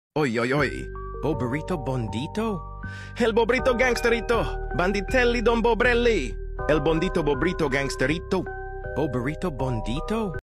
bobrito bandito italian brainrot sound sound effects
bobrito-bandito-italian-brainrot-sound